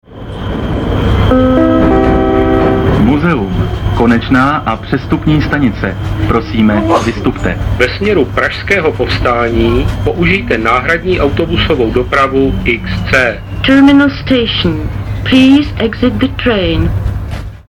Důsledně se dopravní podnik soustředil také na informace poskytované akusticky.
- Vlakový rozhlas: